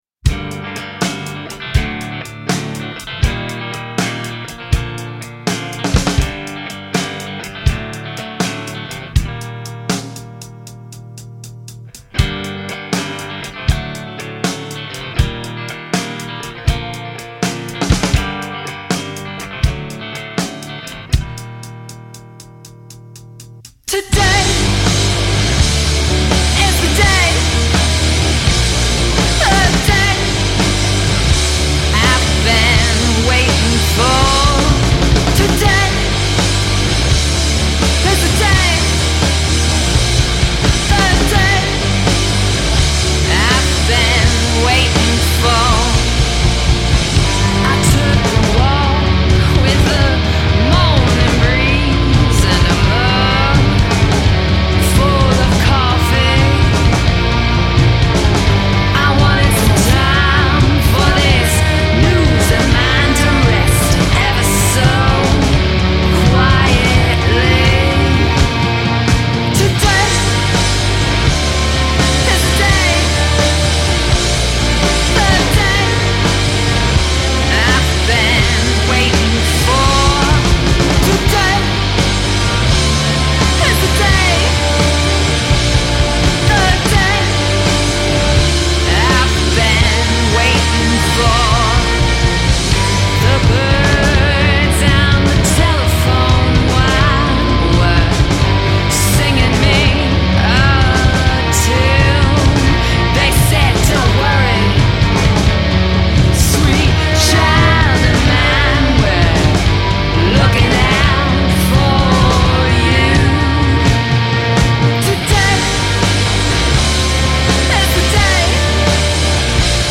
vocals, guitar
drums, bass, guitar, keys